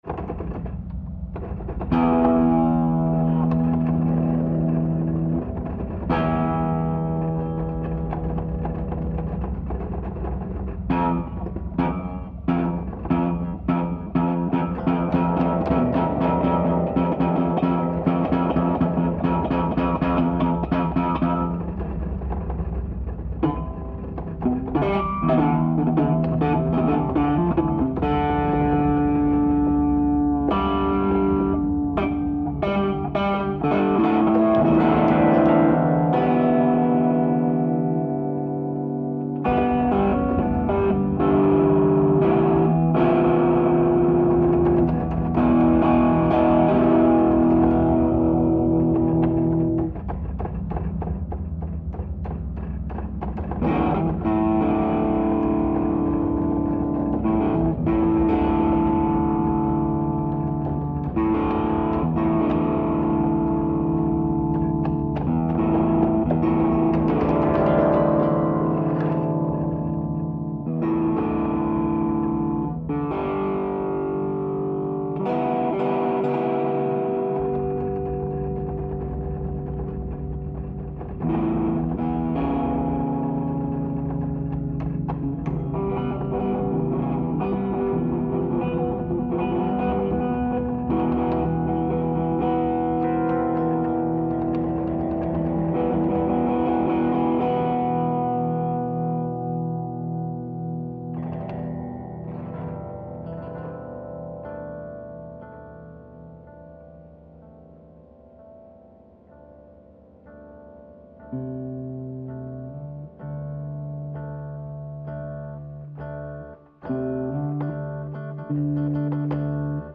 Recorded the day after our first concert.